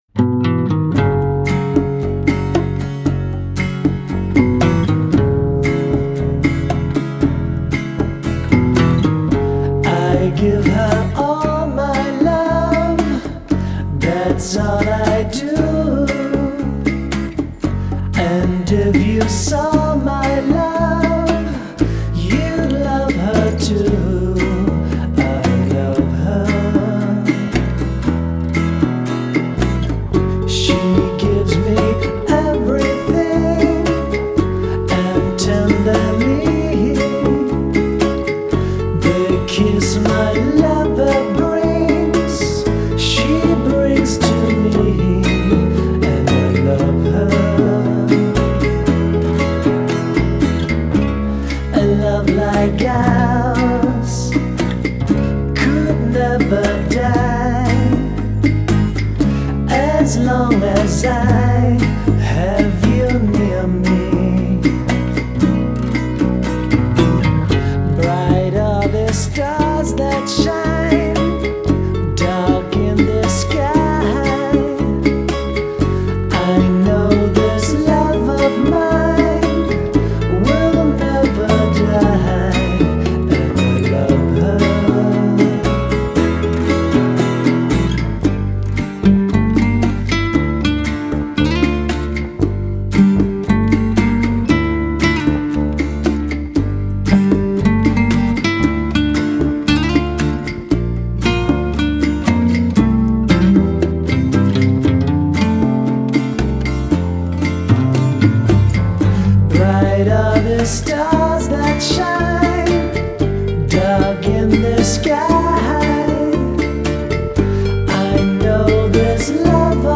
Файл в обменнике2 Myзыкa->Зарубежный рок